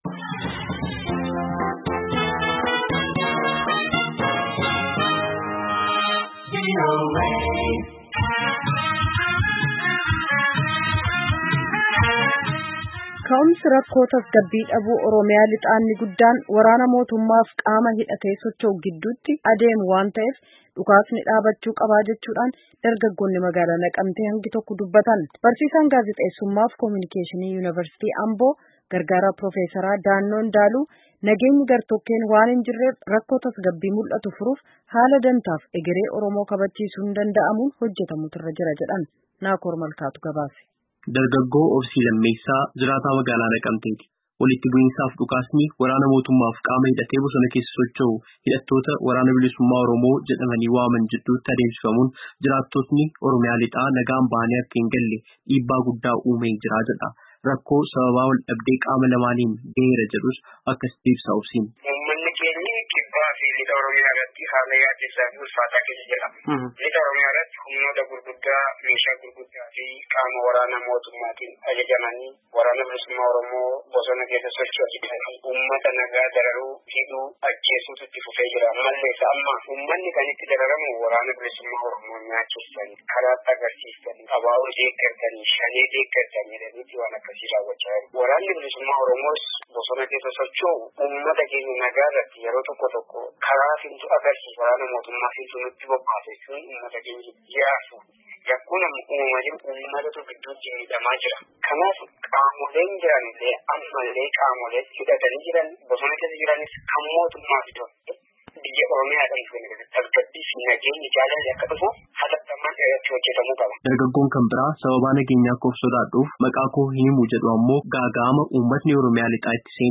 Maddi rakkoo tasgabbii dhabuu Oromiyaa Lixaa inni guddaan waraana mootummaa fi qaama hidhatee socho’u, WBO gidduutti deemu waan taheef karaa itti rakkoon furamu barbaadamuu qaba, jedhu Dargaggootni Magaalaa Naqamtee VOAf yaada isaanii kennan hagi tokko.
Gabaasaa guutuu caqasaa